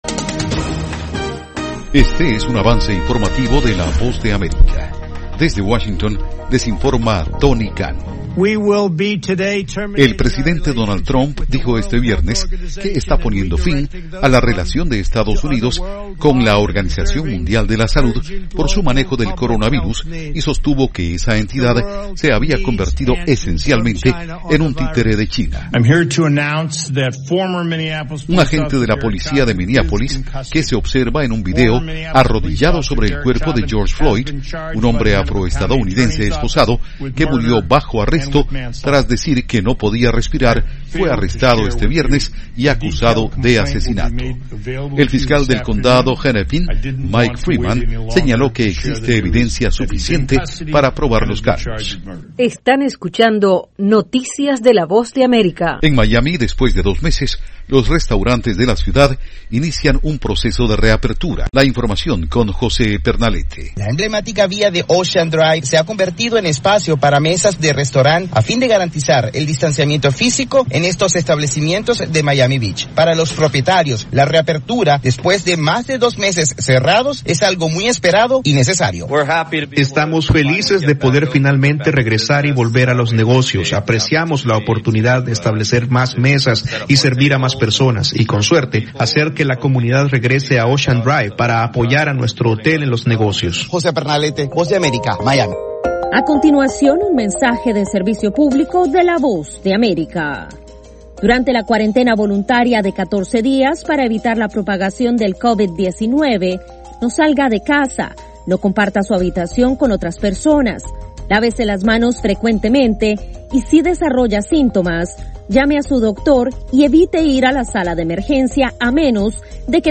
Algunas de las noticias de la Voz de América en este avance informativo: